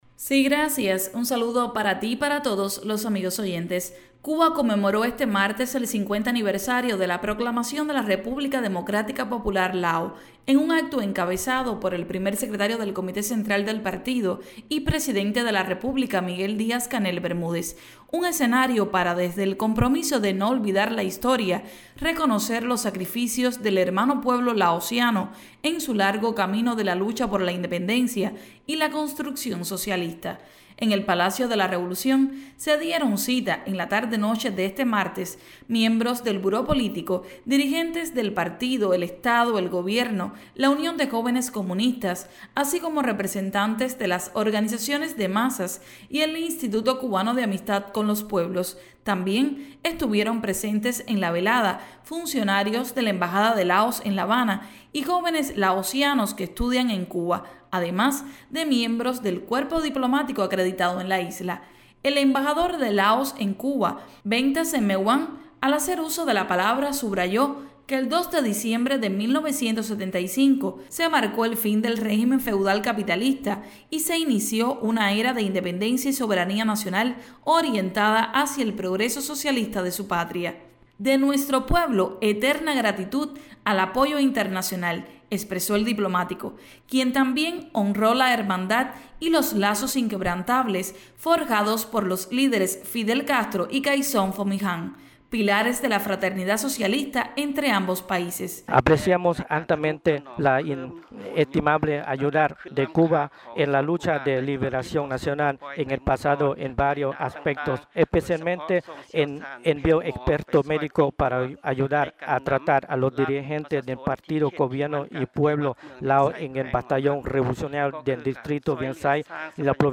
Encabezó Primer Secretario del Comité Central del Partido y Presidente de la República, Miguel Díaz-Canel Bermúdez, celebración por el 50 Aniversario de la Proclamación de la República Democrática Popular Lao.